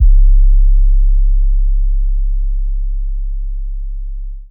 808 (Successful).wav